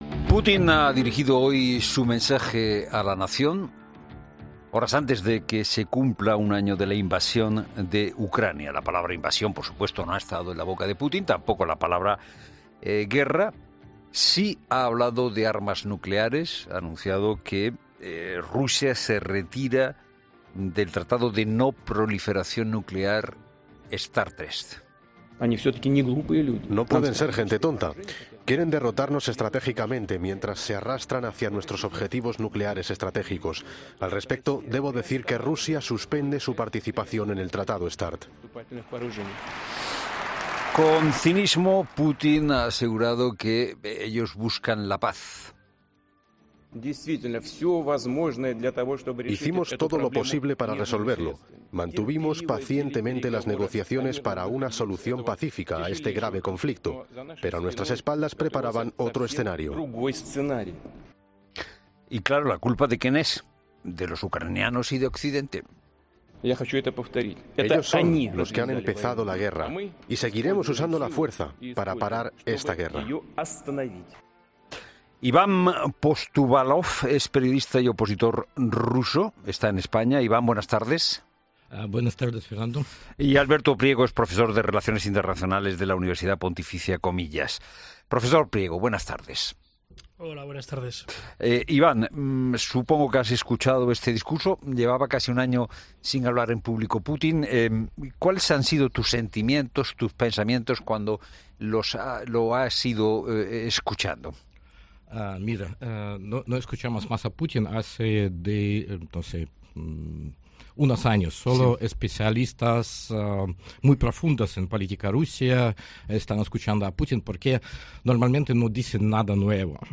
Ambos invitados han analizado y aportado su opinión sobre el último discurso de Putin.